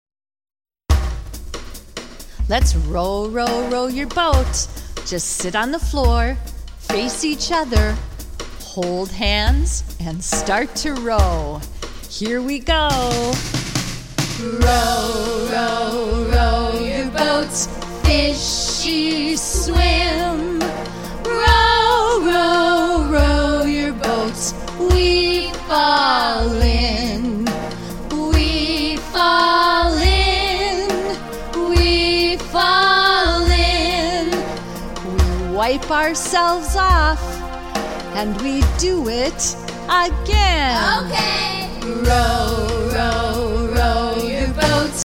Interaction song